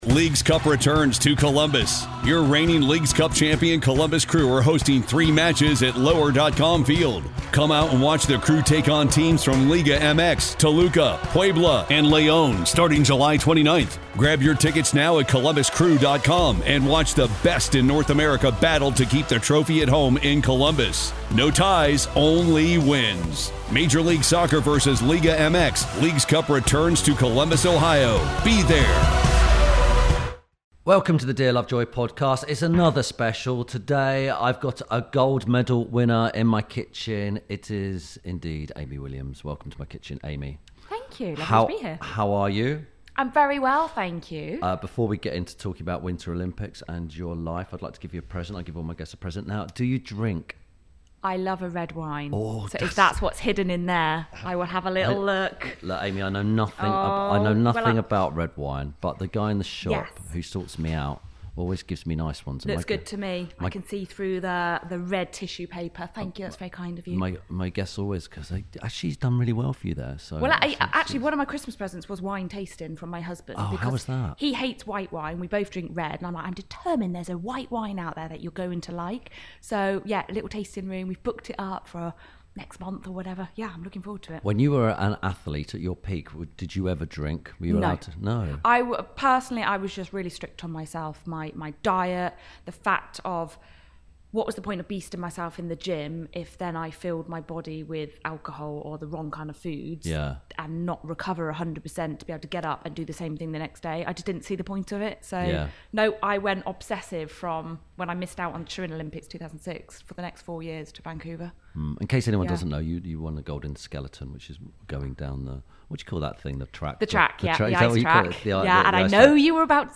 Ep. 62 – AMY WILLIAMS MBE – Half A Second Can Change Your Life – INTERVIEW SPECIAL
This week Tim Lovejoy talks to Olympic gold medalist Amy Williams about why seconds matter, the art of being an Olympic athlete and “it’s not a tea tray”.